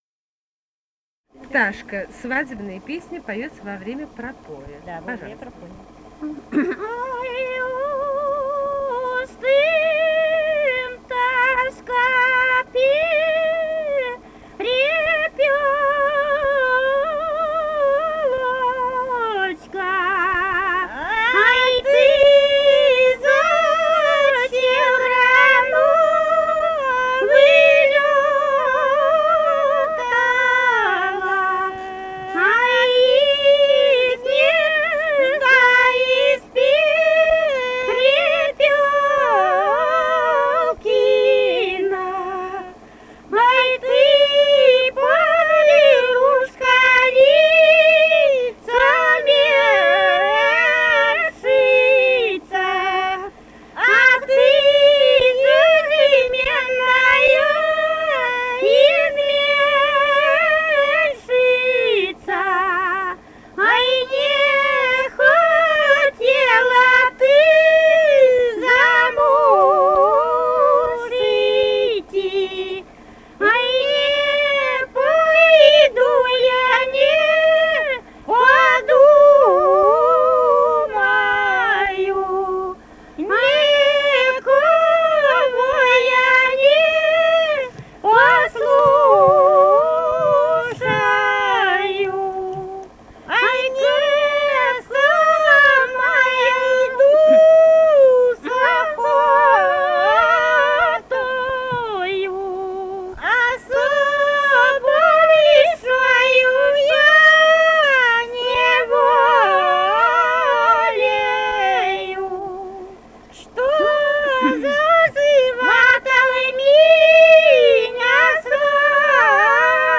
полевые материалы
Костромская область, с. Дымница Островского района, 1964 г. И0789-22